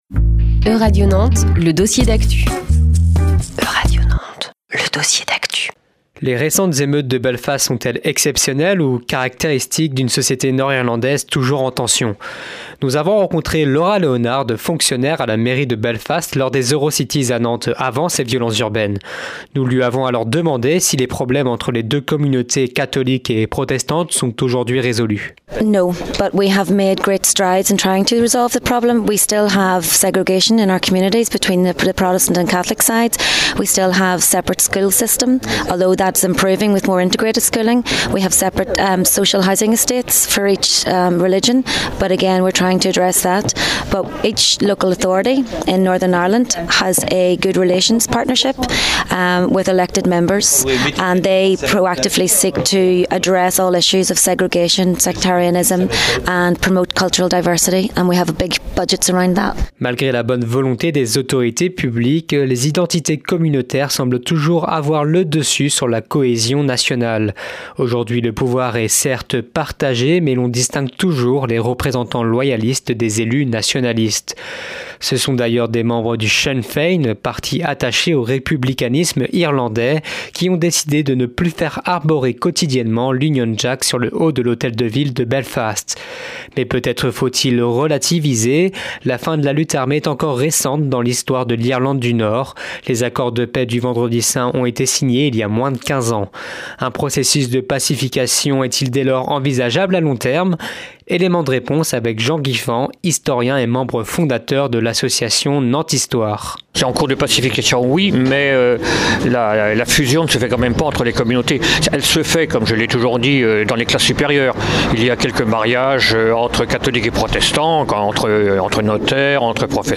Guy-Michel Chauveau, député-maire de la Flèche et Carine Ménage conseillère régionale invités de Radio Prévert
Interview